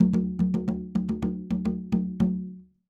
Conga Fill 03.wav